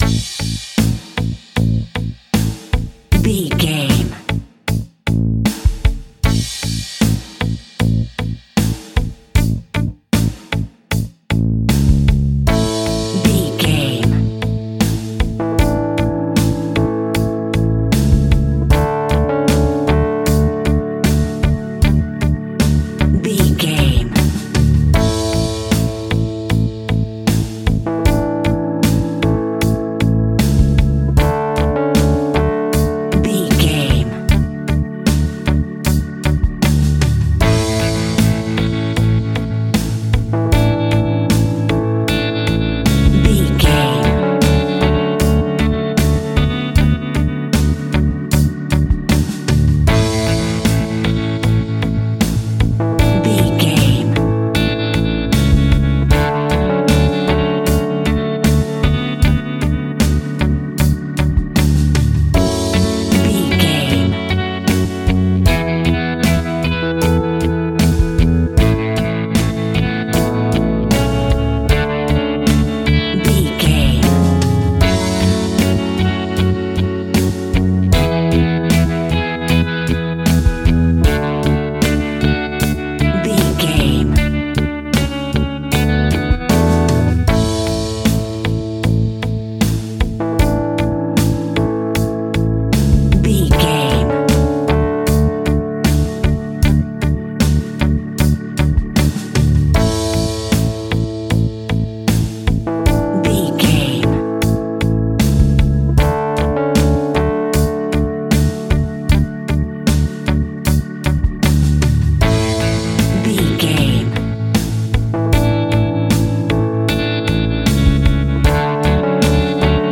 Eighties Rock Ballad.
Ionian/Major
pop rock
energetic
uplifting
drums
bass guitar
electric guitar
keyboards
brass